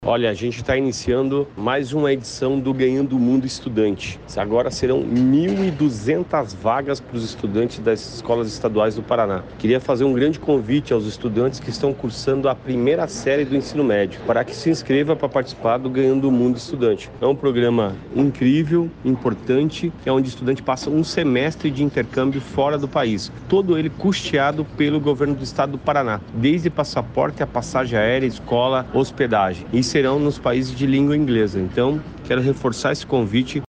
Sonora do secretário da Educação, Roni Miranda, sobre a abertura das inscrições para a edição 2025 do programa Ganhando o Mundo